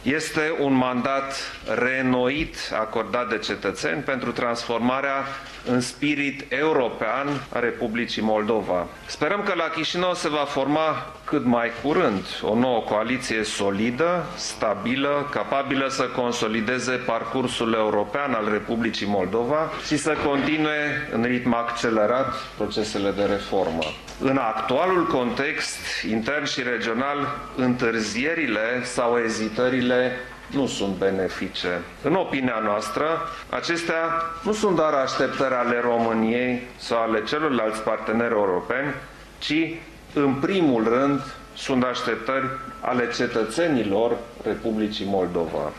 Şeful statului român a salutat opţiunea pro-europeană exprimată de cetăţenii moldoveni la recentele alegeri locale: